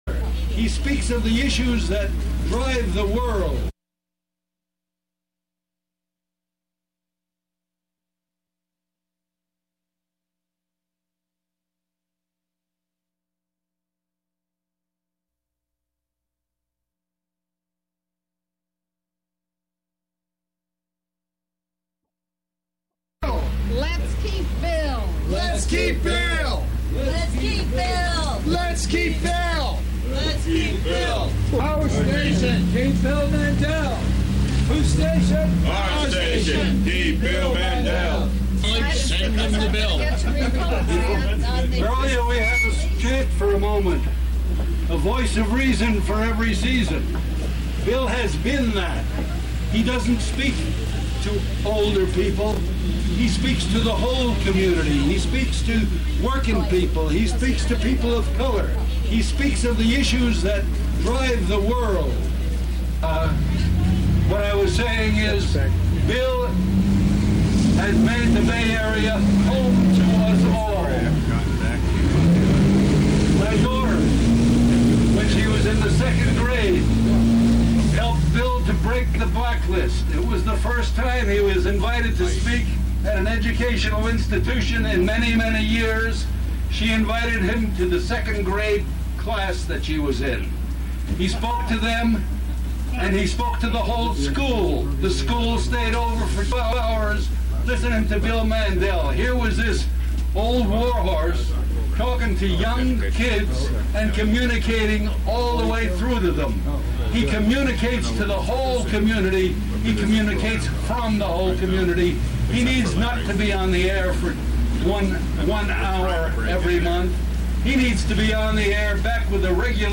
This audio-clip is made up of voices from the outside; a rally locked-out of the KPFA building, and not allowed to observe the meeting or vote.